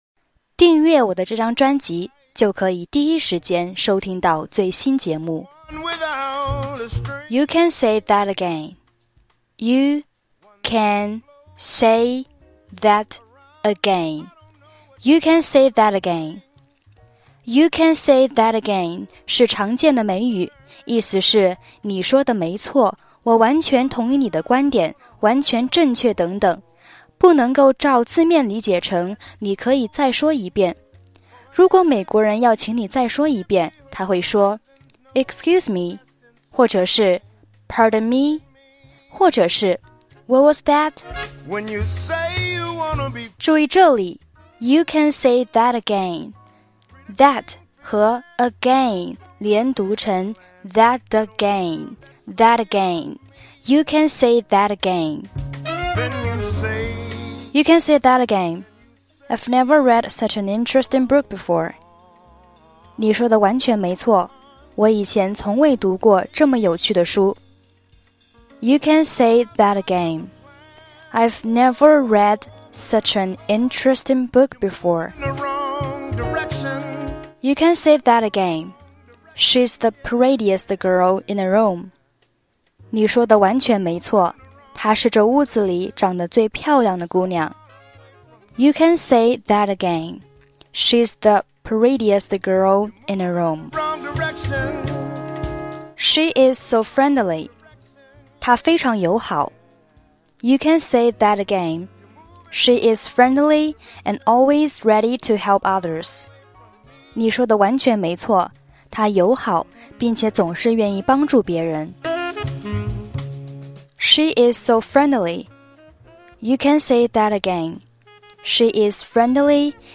背景音乐：